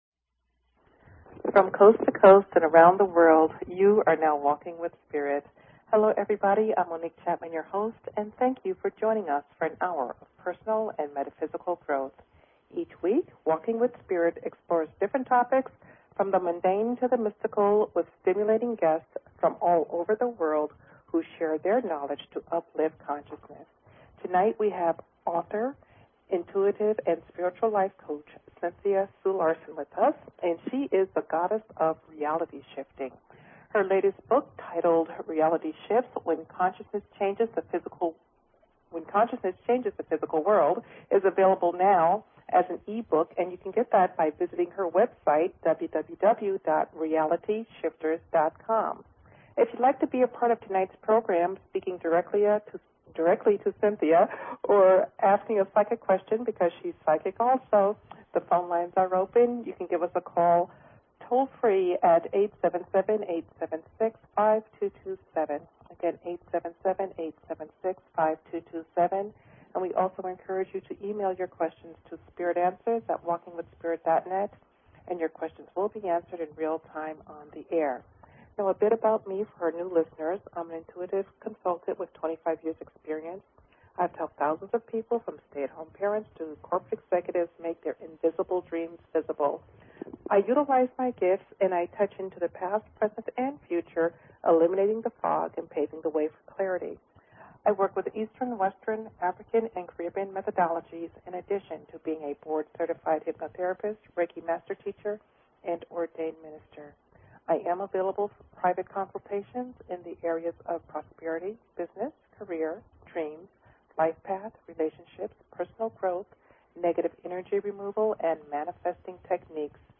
Talk Show Episode, Audio Podcast, Walking_with_Spirit and Courtesy of BBS Radio on , show guests , about , categorized as